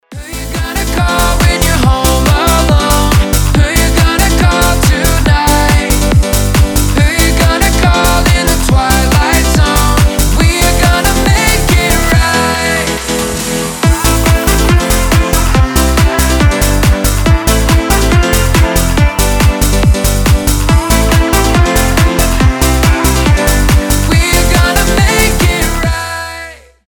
танцевальные
edm , быстрые , евродэнс